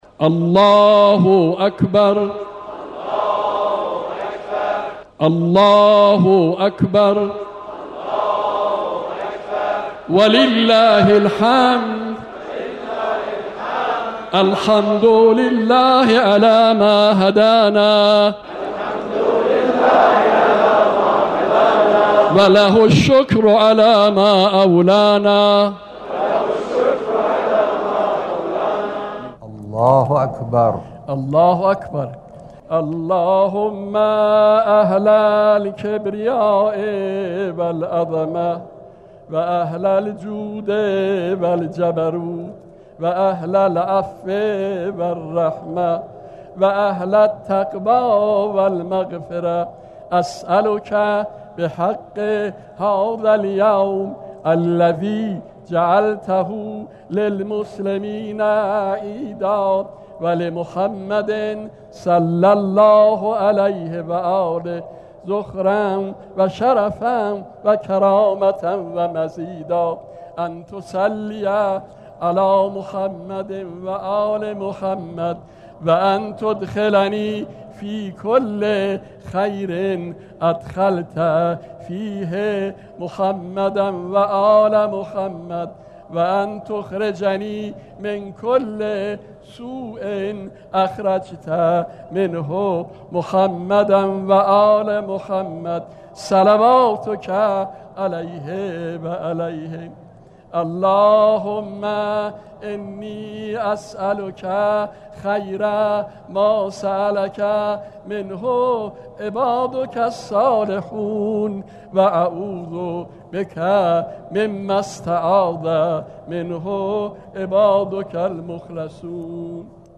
دعای قنوت نماز عید سعید فطر